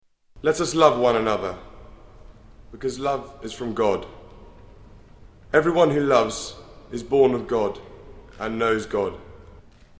Prince William gives reading